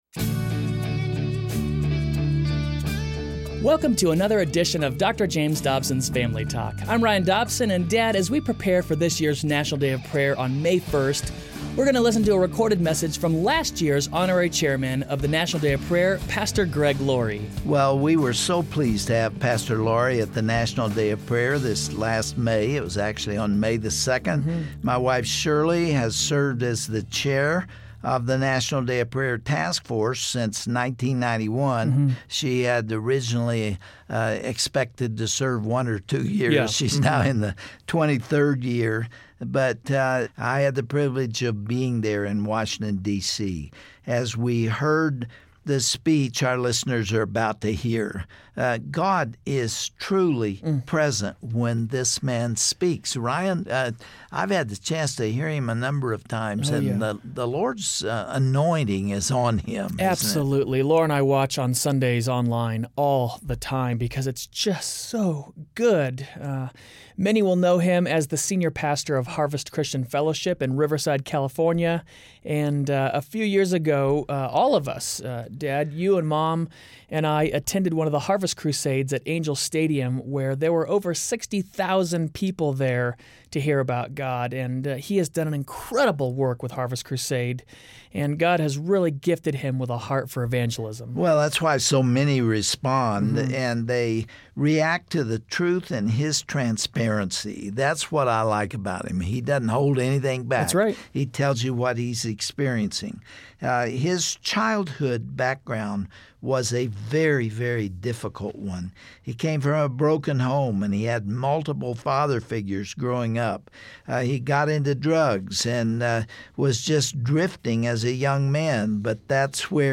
Greg Laurie gives the Key Note Address at the National Day of Prayer, May 2, 2013.